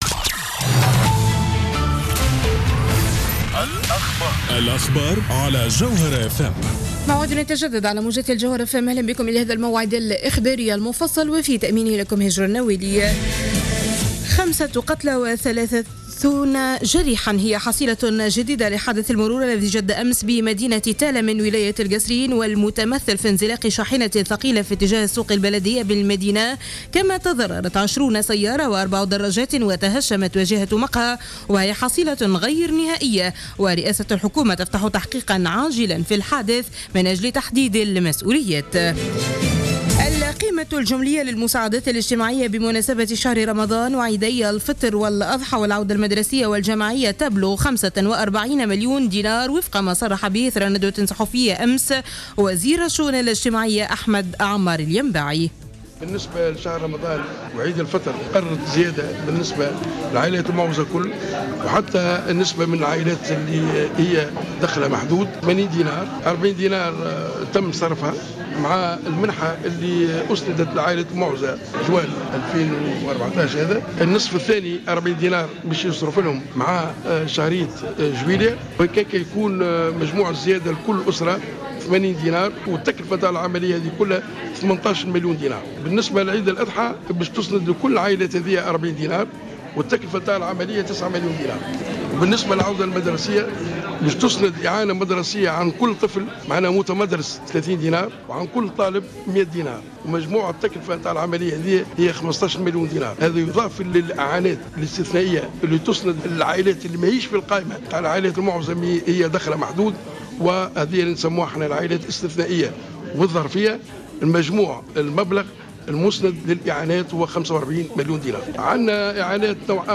نشرة أخبار منتصف الليل ليوم الجمعة 27-06-14